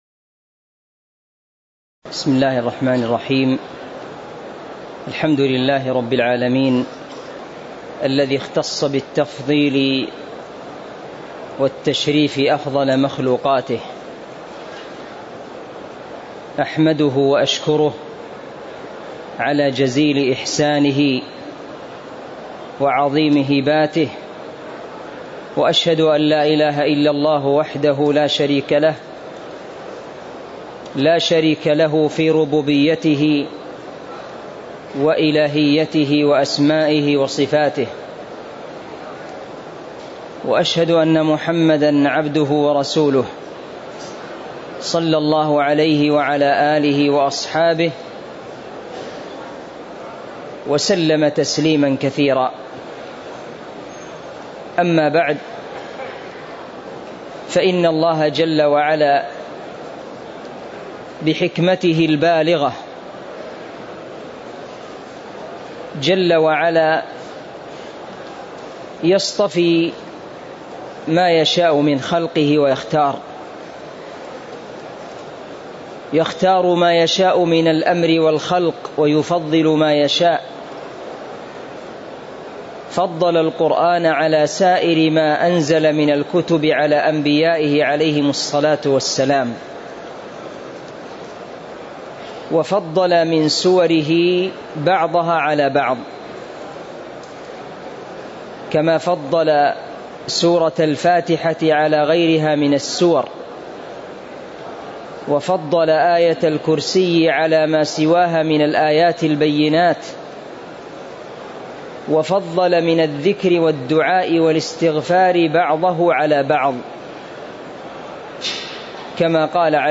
تاريخ النشر ٦ محرم ١٤٤٥ هـ المكان: المسجد النبوي الشيخ